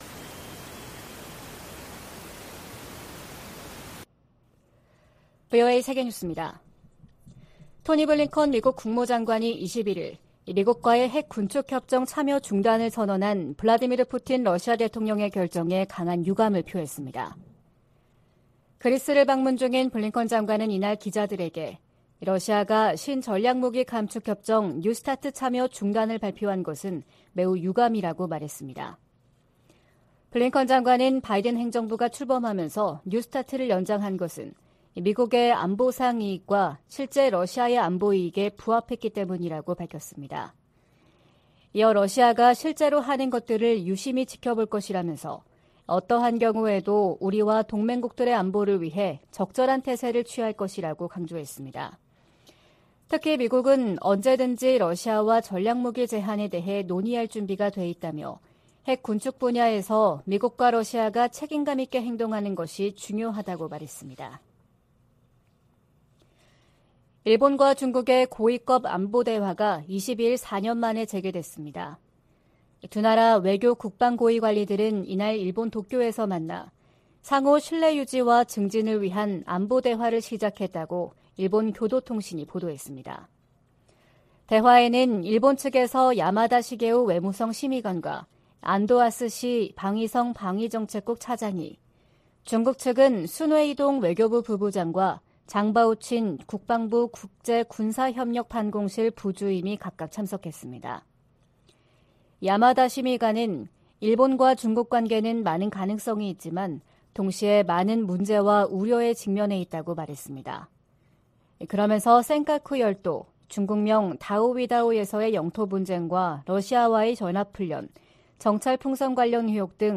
VOA 한국어 '출발 뉴스 쇼', 2023년 2월 23일 방송입니다. 미 하원 군사위원장이 북한의 미사일 위협에 맞서 본토 미사일 방어망을 서둘러 확충할 것을 바이든 행정부에 촉구했습니다.